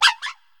Cri de Pohm dans Pokémon HOME .